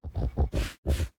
Minecraft Version Minecraft Version 25w18a Latest Release | Latest Snapshot 25w18a / assets / minecraft / sounds / mob / sniffer / searching4.ogg Compare With Compare With Latest Release | Latest Snapshot
searching4.ogg